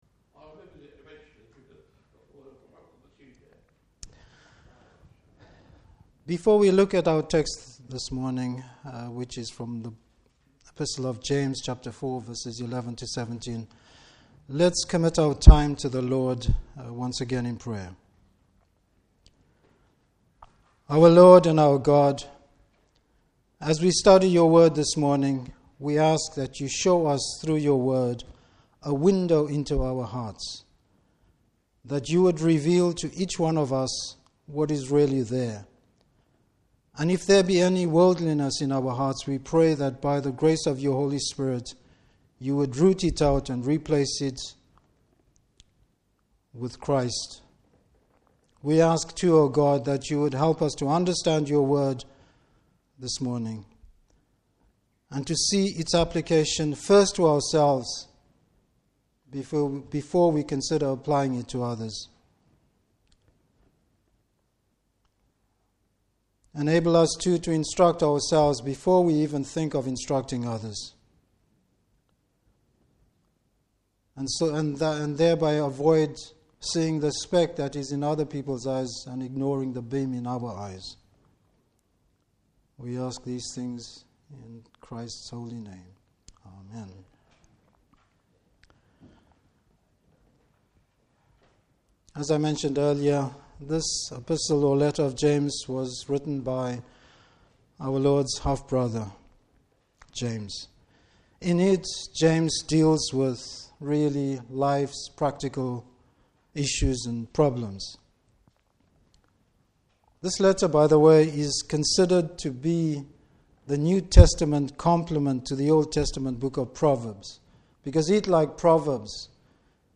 Passage: James 4:11-17. Service Type: Morning Service Godly consideration.